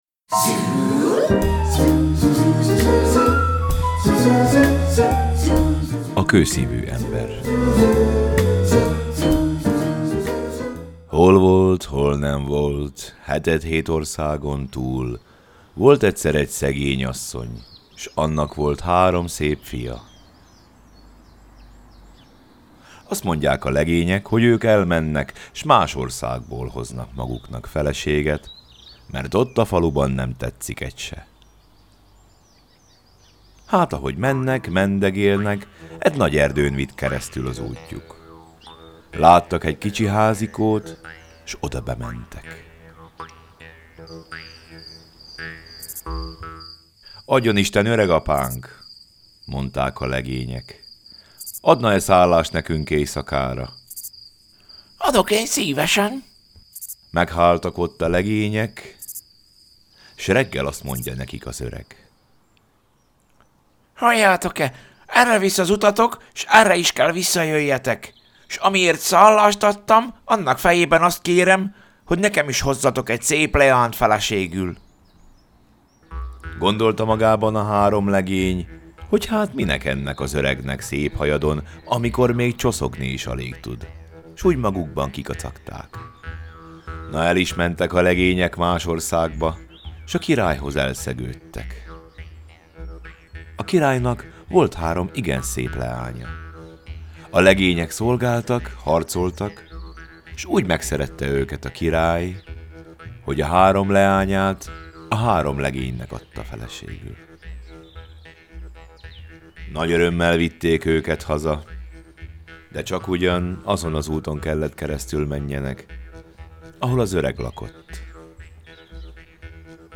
A Magyar Népmesék, A selyemrét és más mesék c. könyvből elhangzik a Kőszívű ember c. mese.
Barozda – Jew’s Harp Music